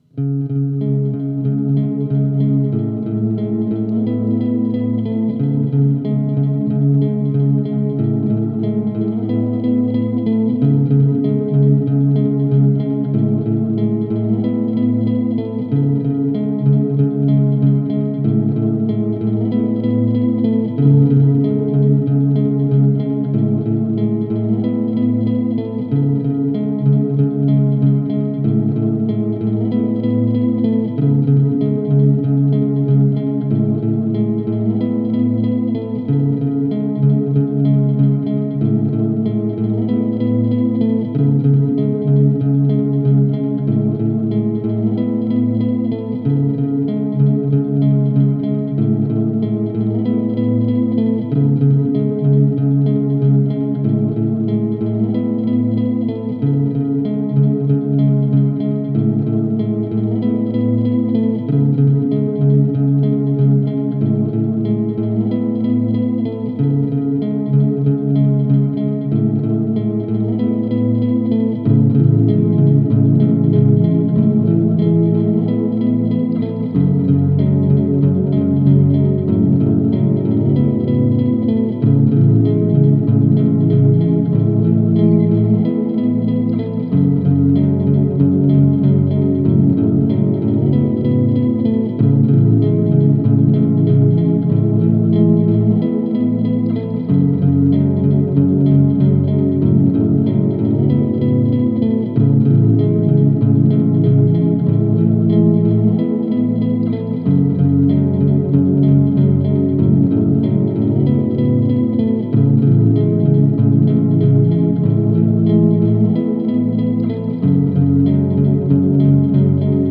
ambient guitar music
ambient music See all items with this value